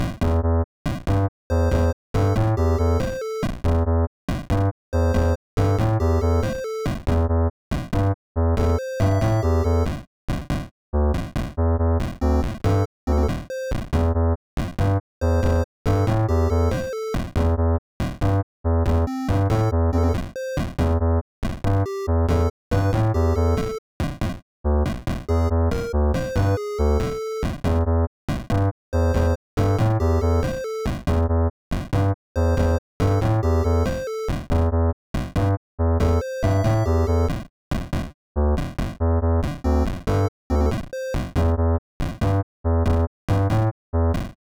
Sounds like it can work for a Title Screen or Options Menu!
Thanks! I was going for a retro-like style.